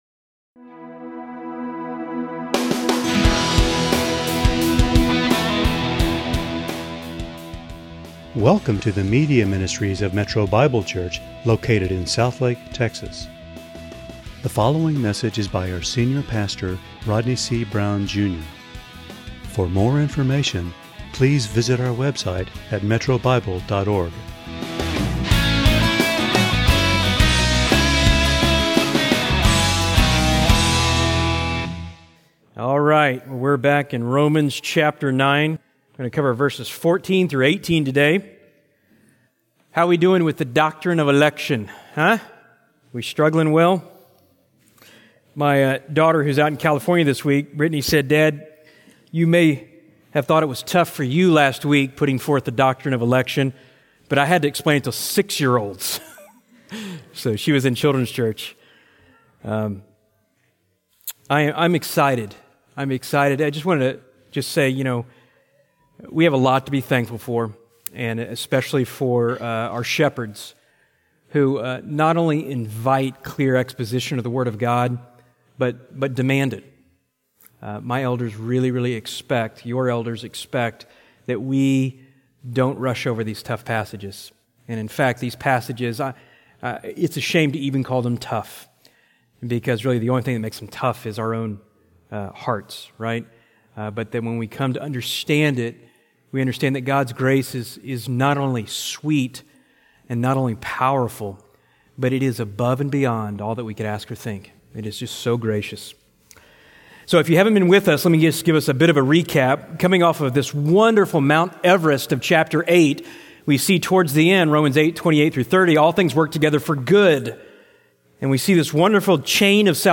× Home About sermons Give Menu All Messages All Sermons By Book By Type By Series By Year By Book That’s Not Fair!